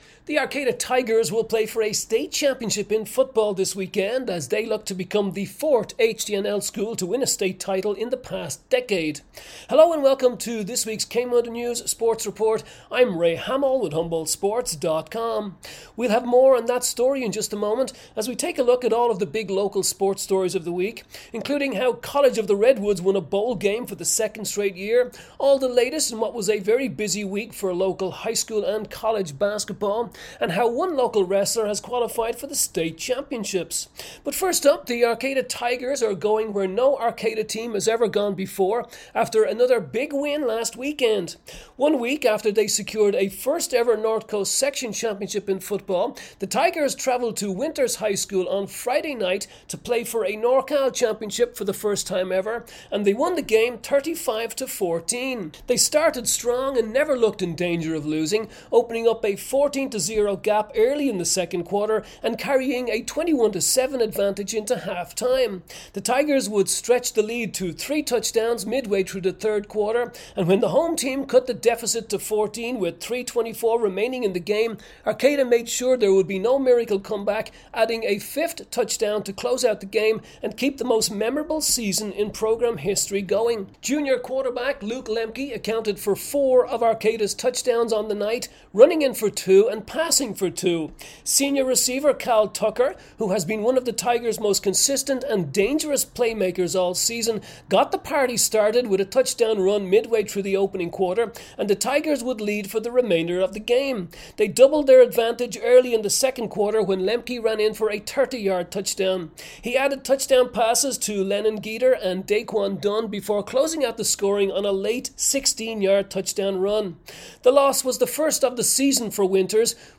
DEC 12 KMUD News sports report